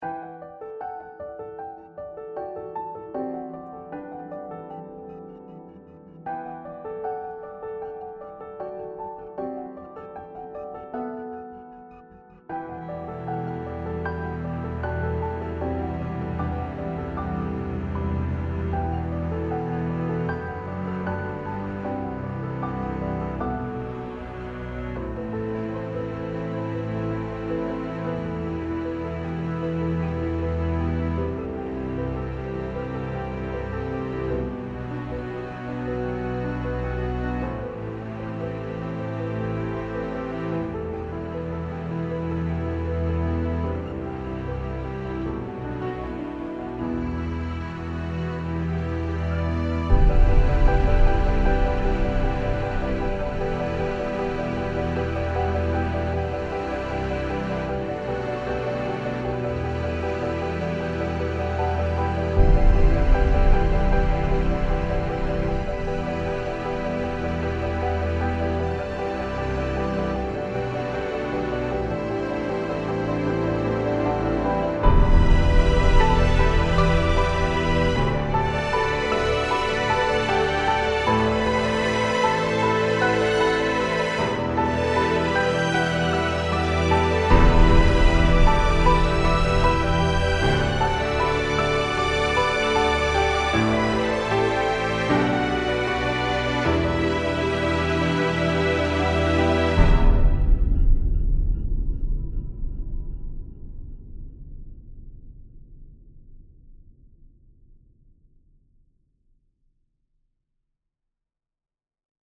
piano - intimiste - romantique - aerien - melodieux